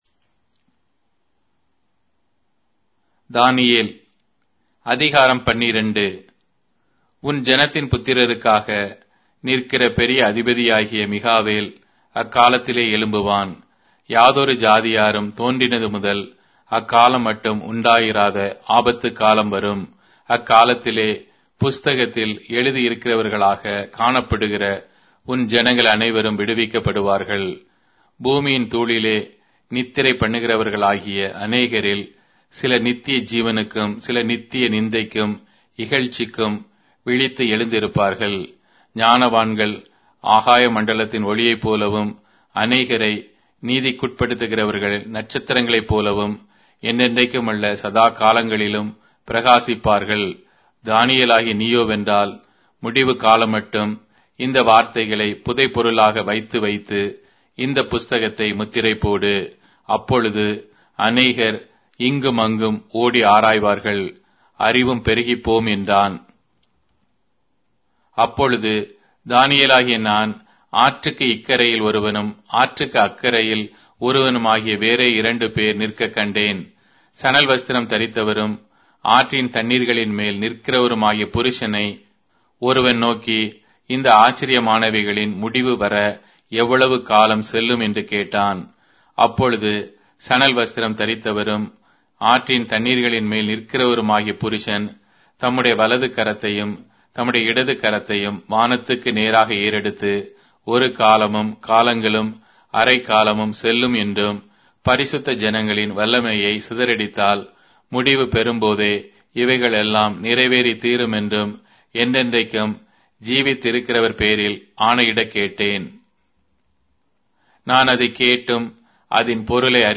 Tamil Audio Bible - Daniel 6 in Irvgu bible version